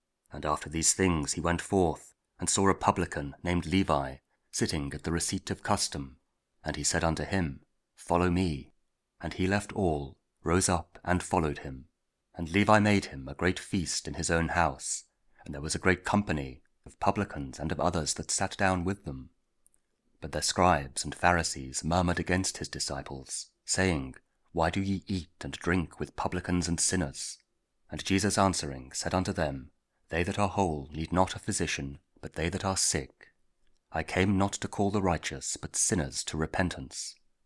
Luke 5: 27-32 – Saturday after Ash Wednesday (Audio Bible, Spoken Word)